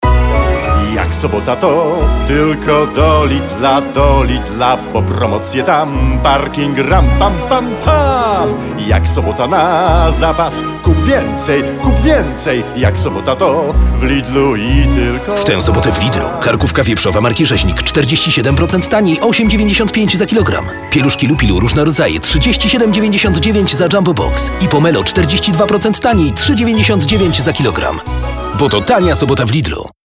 znaną i lubianą polską melodię